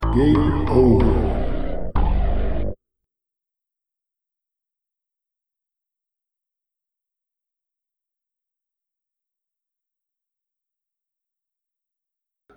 voice_game_over.wav